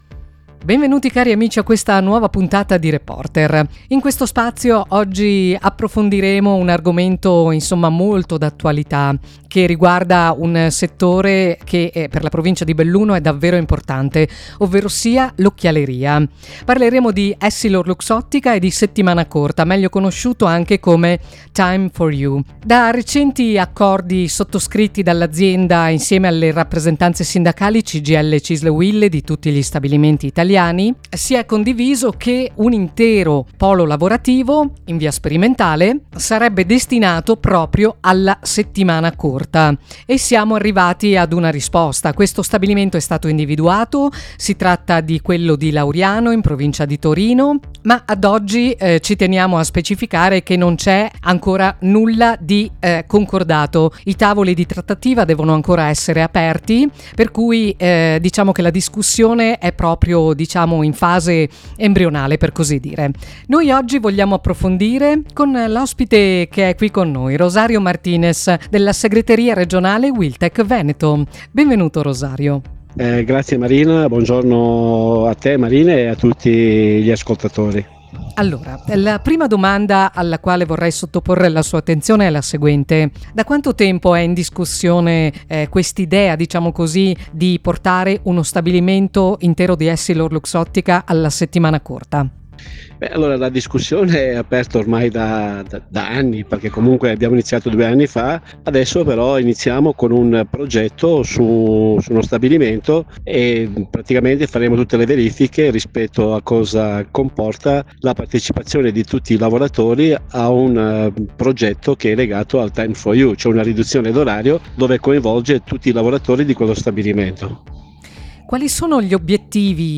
dal giornale radio principale del 19 settembre 2025